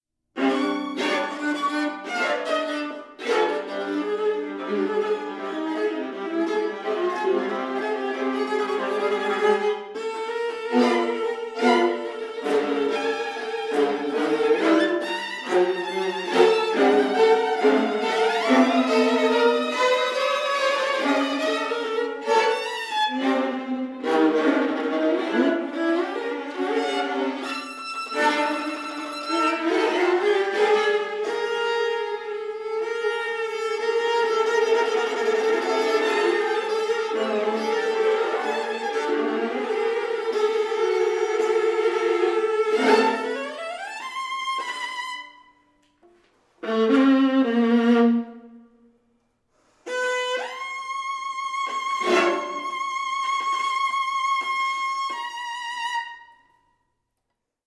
stereo
for 7 violas
piano